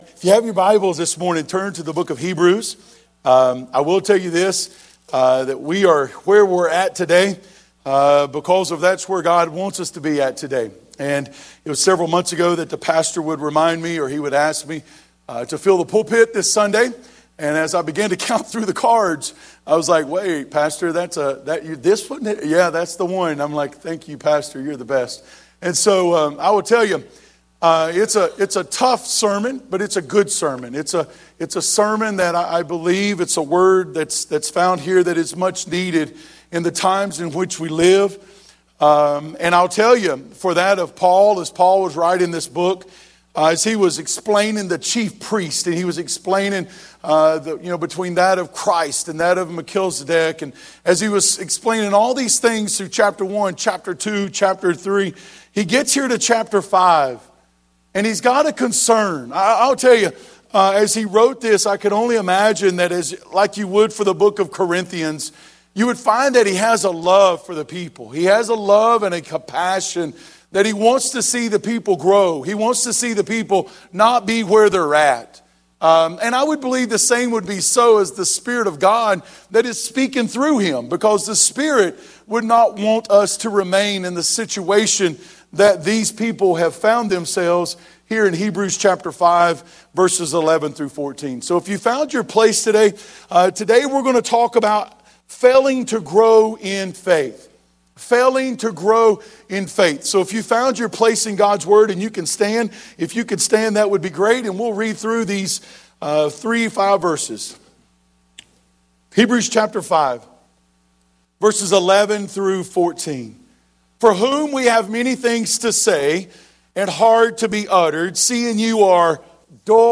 Sunday Morning Worship Passage